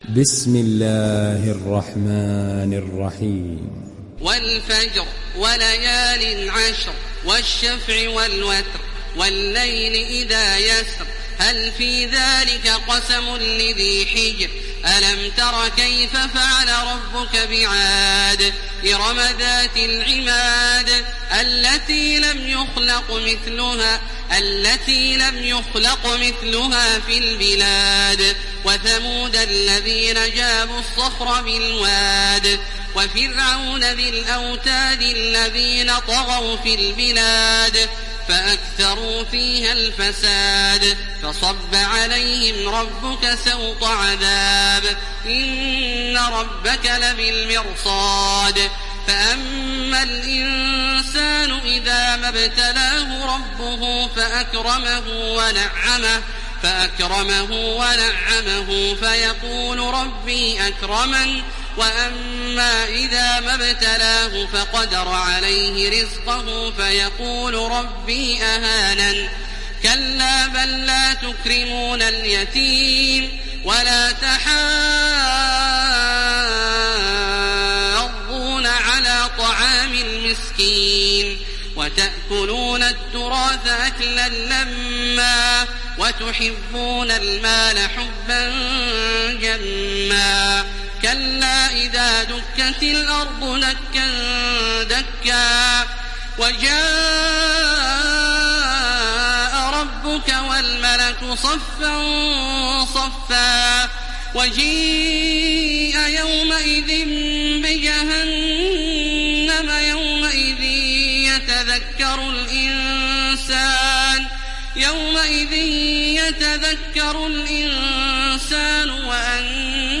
İndir Fecr Suresi Taraweeh Makkah 1430